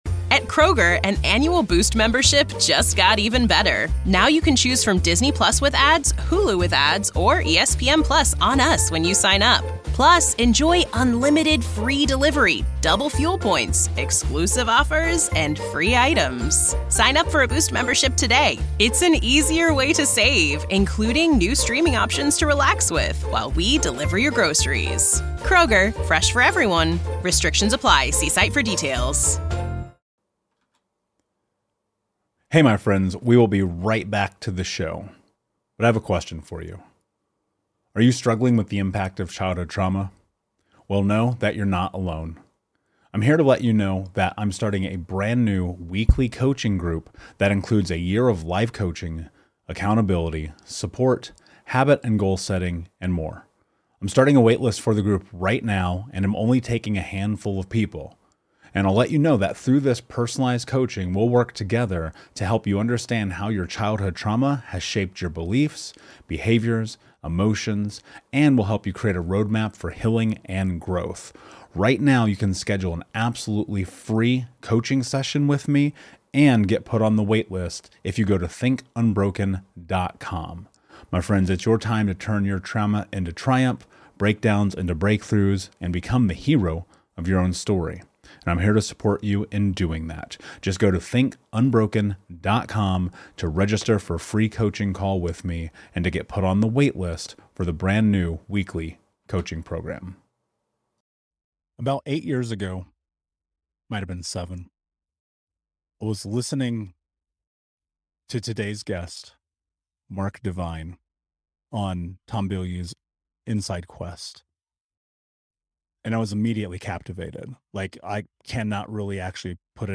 It's an honor for me today to sit across from him and have this conversation on this podcast.